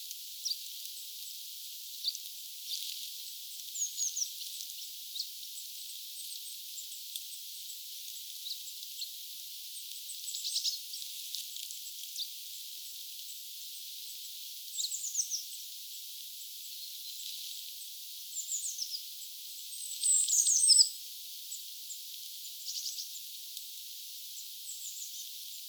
tuollaisia sinitiaislintujen pikku ääniä
tuollaisia_sinitiaislinnun_pikkuaania.mp3